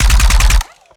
Added more sound effects.
GUNAuto_RPU1 Burst_02_SFRMS_SCIWPNS.wav